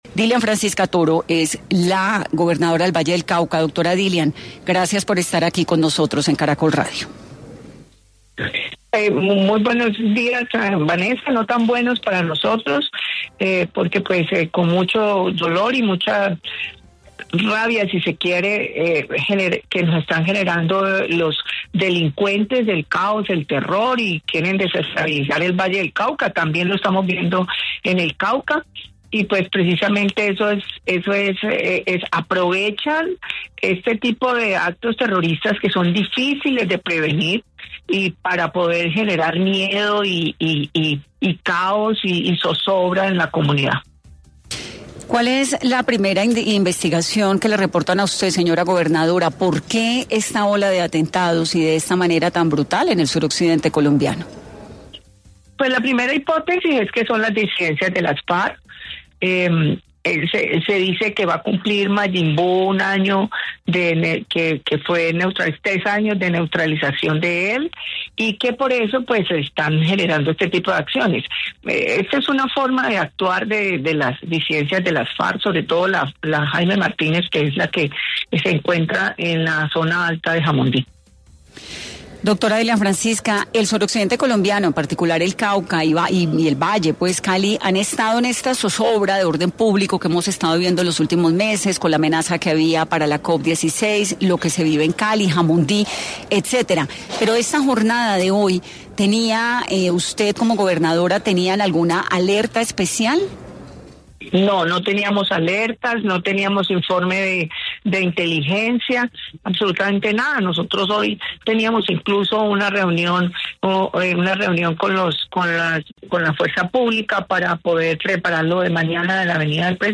Dilian Francisca Toro, gobernadora del Valle del Cauca, expresó en 10AM que no tenían ninguna alerta, ni información de los atentados y es necesario un Consejo de Seguridad.